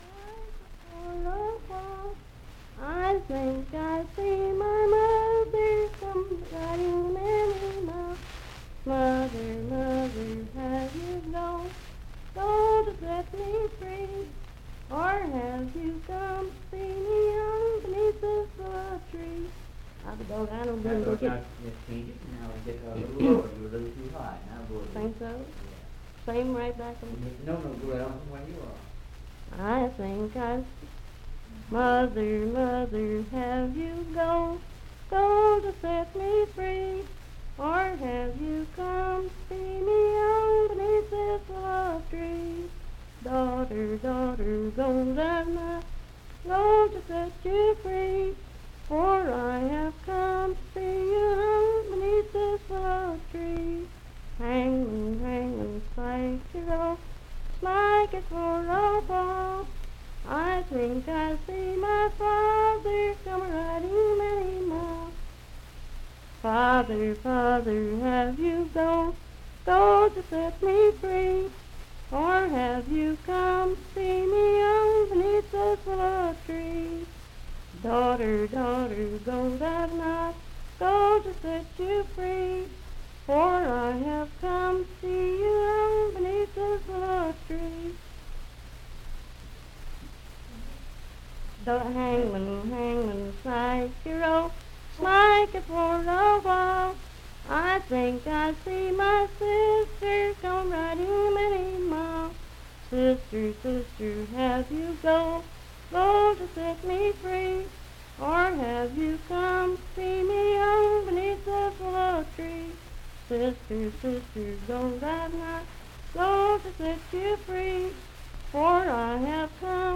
Unaccompanied vocal music
Voice (sung)
Hardy County (W. Va.)